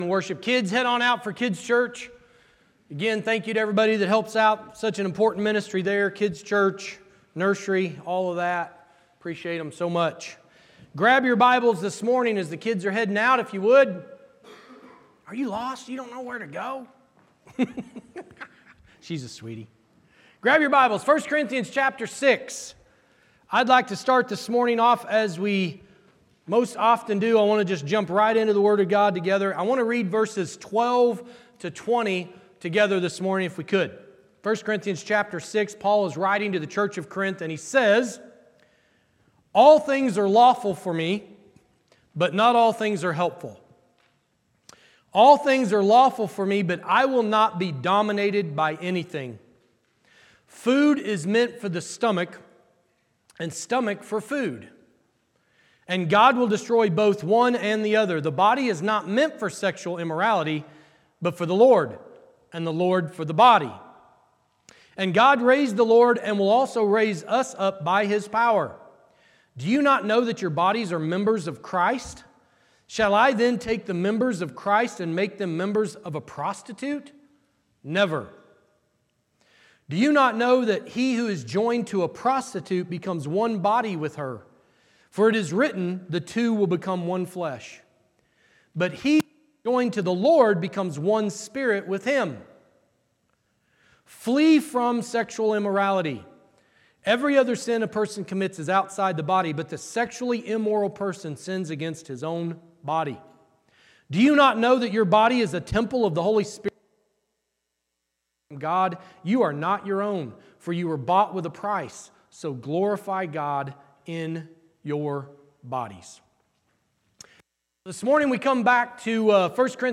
Church in Action Sermon Podcast October 26, 2025 Play Episode Pause Episode Mute/Unmute Episode Rewind 10 Seconds 1x Fast Forward 30 seconds 00:00 / 33:28 Subscribe Share Spotify RSS Feed Share Link Embed